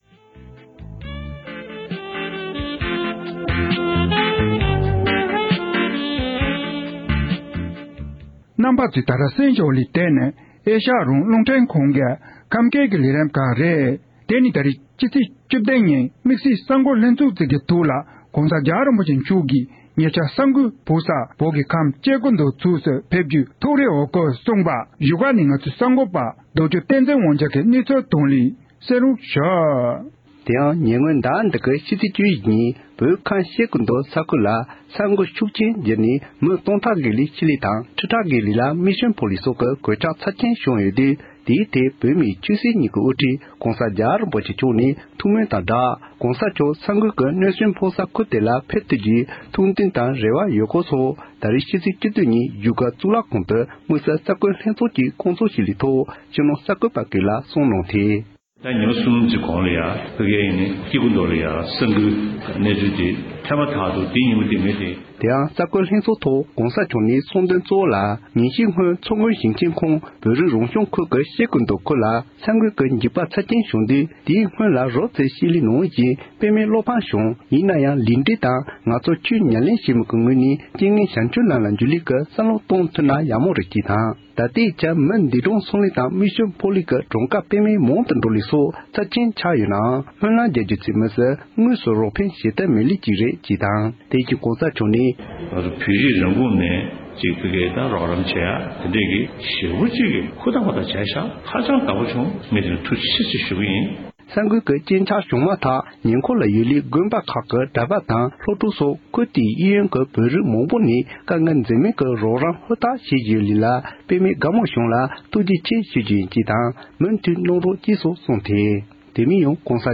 བཞུགས་སྒར་དུ་༸གོང་ས་མཆོག་ནས་གསར་འགོད་ལྷན་ཚོཊ།
སྒྲ་ལྡན་གསར་འགྱུར། སྒྲ་ཕབ་ལེན།